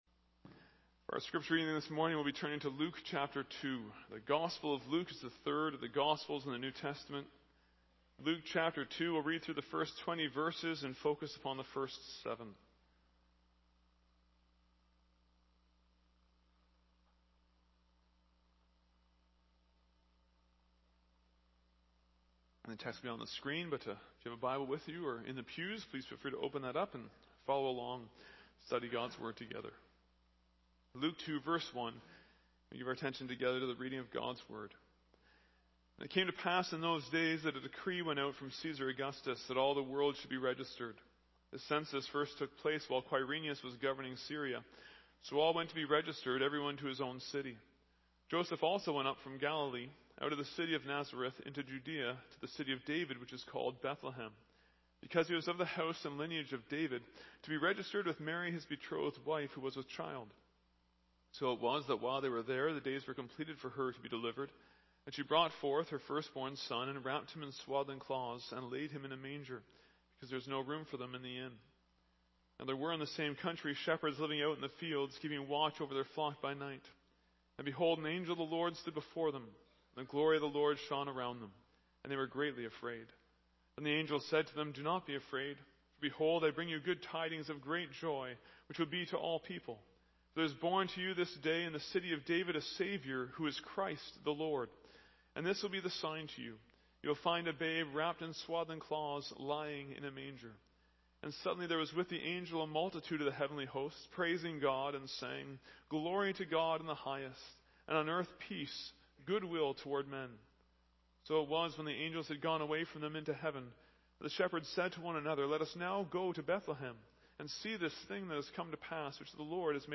Non-Series Sermon